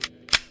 Gun Cock2.wav